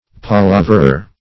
Palaverer \Pa*la"ver*er\, n. One who palavers; a flatterer.